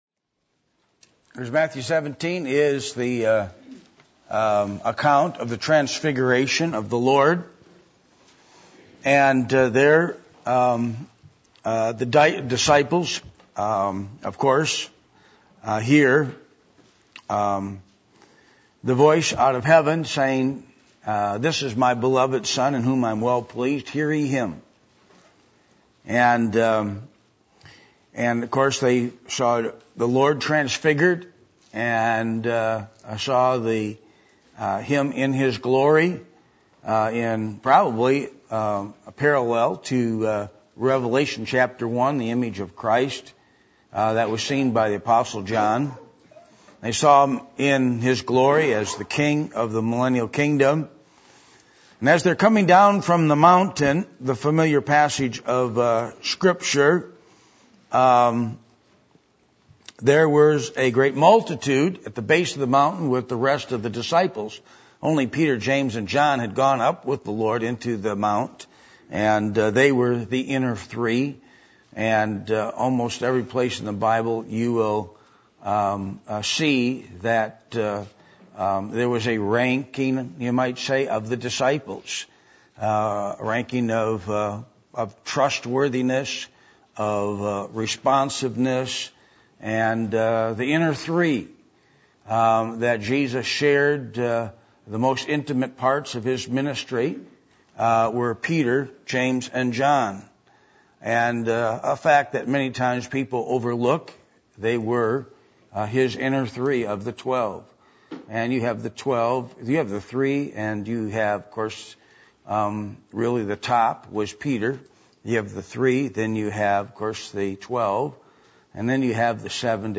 Passage: Matthew 17:14-21 Service Type: Midweek Meeting